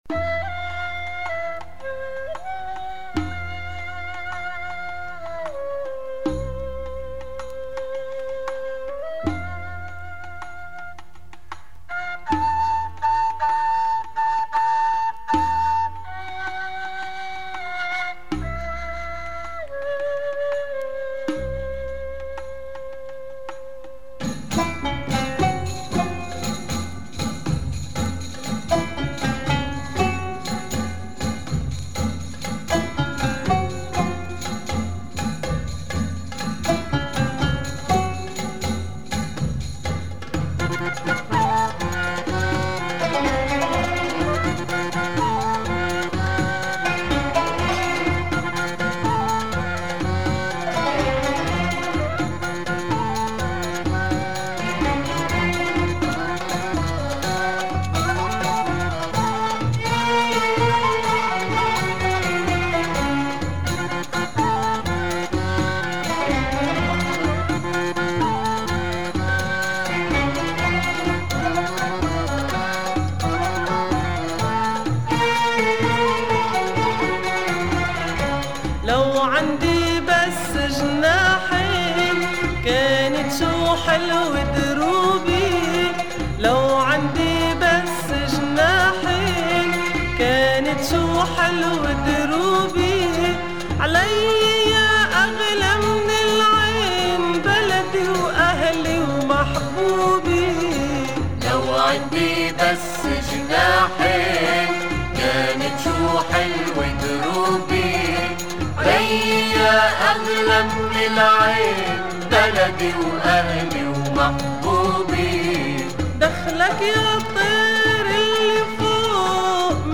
female Lebanese singer